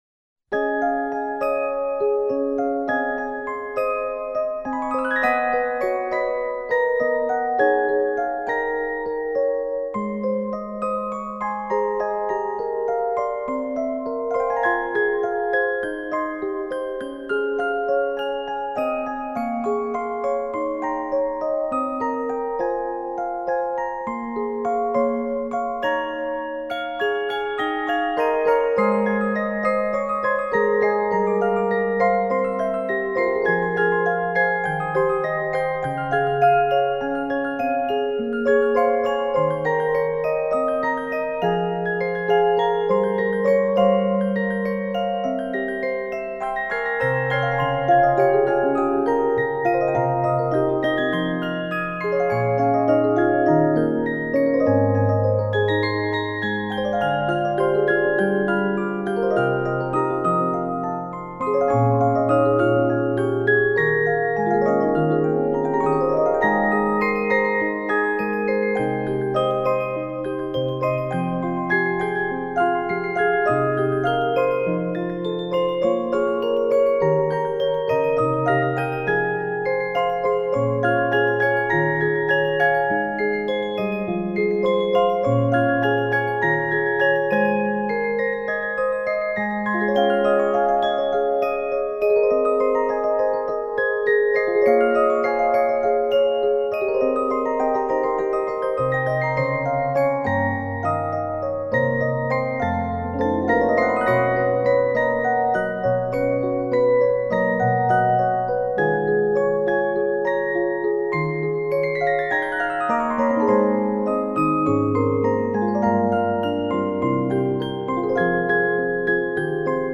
(no vocals)